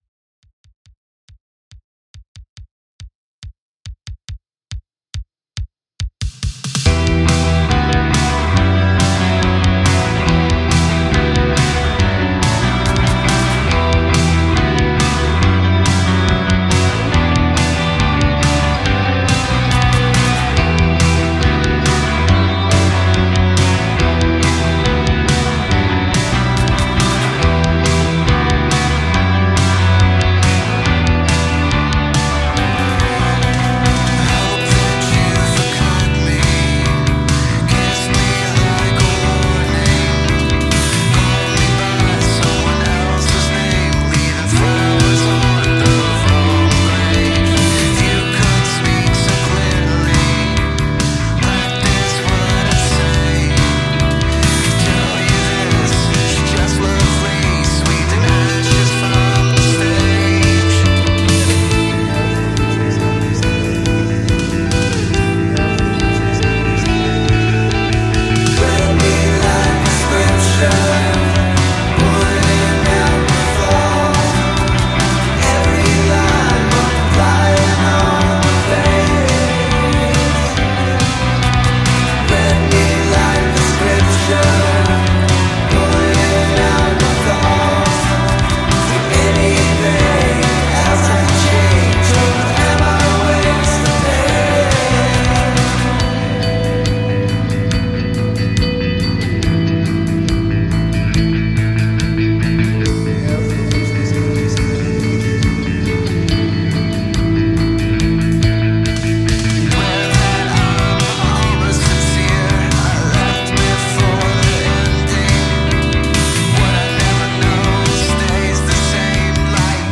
Gothic Rock, Dreampop, Alternative